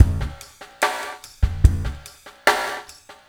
BOL GASP  -L.wav